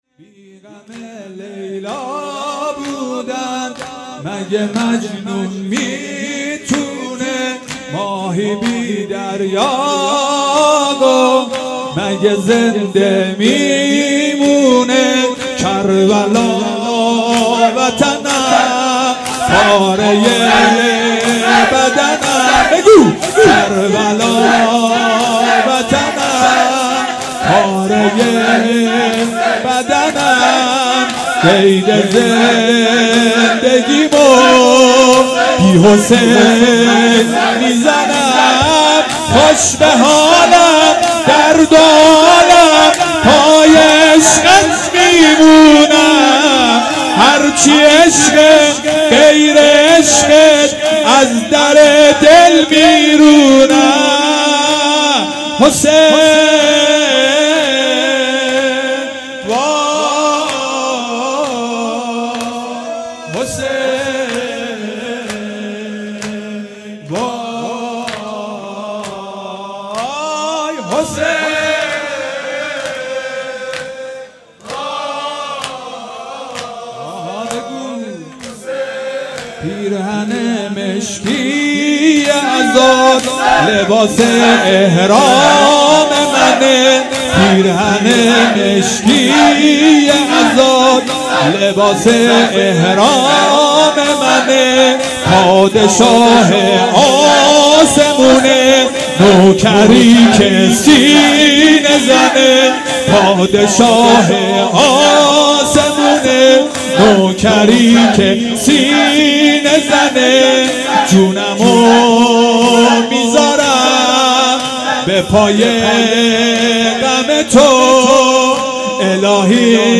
شب دوم محرم الحرام 1441